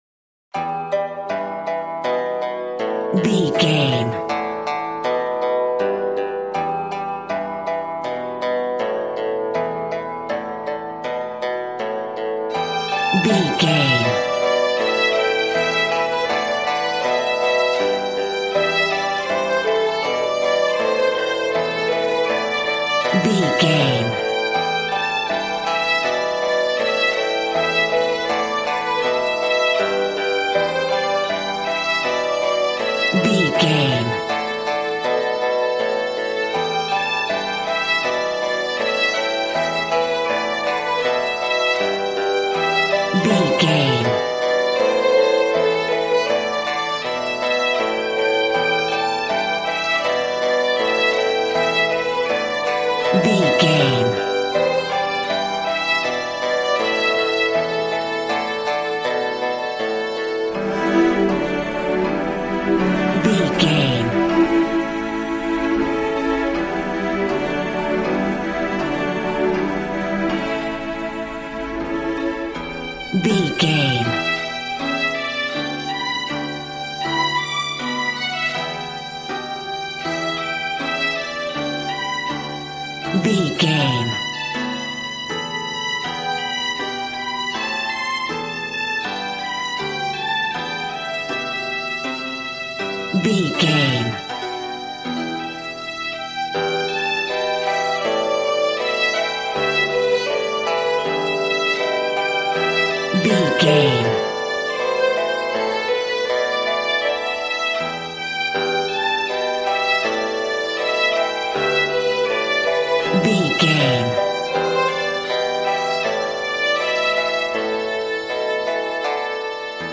Aeolian/Minor
happy
bouncy
conga